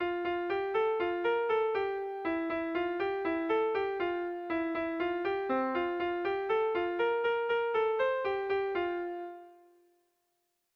Belea dago haitzean - Bertso melodies - BDB.
Irrizkoa
ABD